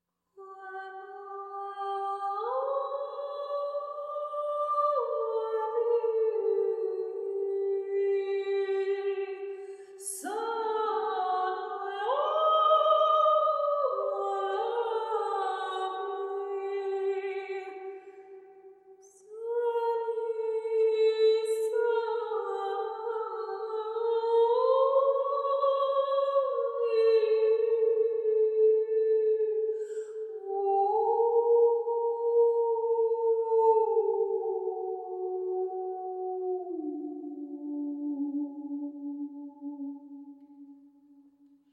Acapella                    Durée 07:40